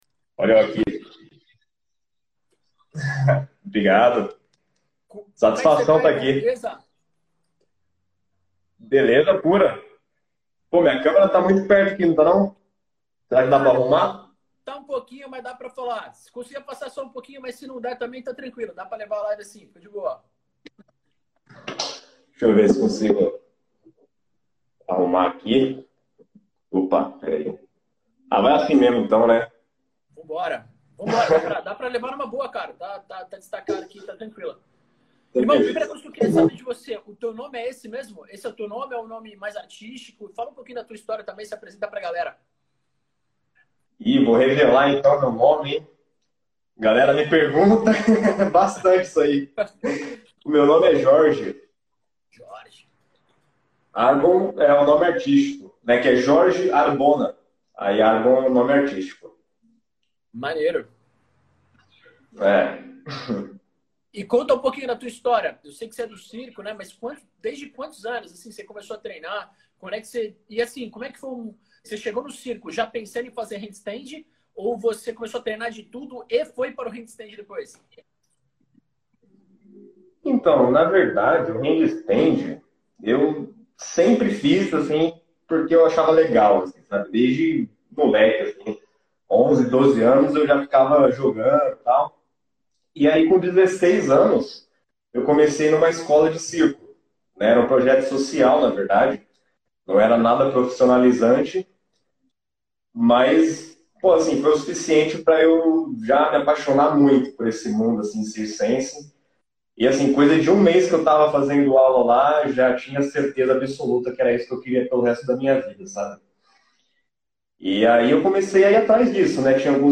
HandStand: como executar Calistenia Online Podcast